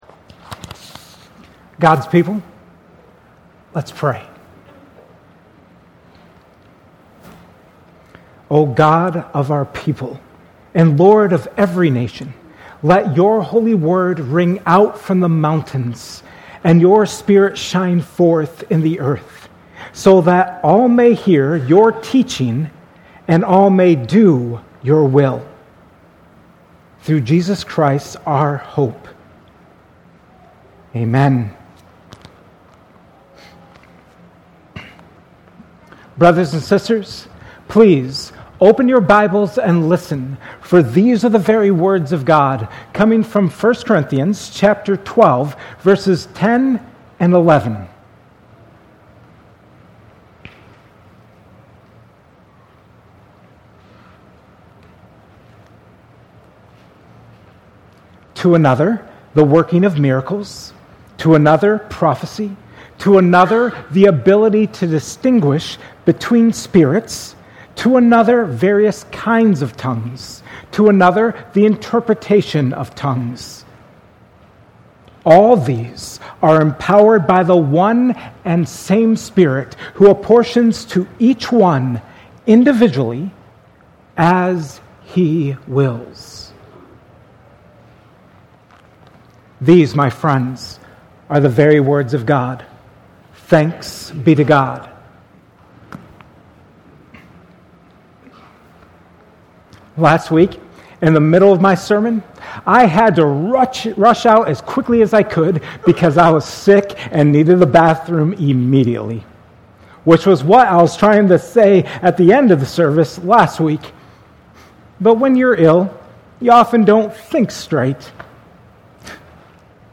2025 at Cornerstone Church in Pella, IA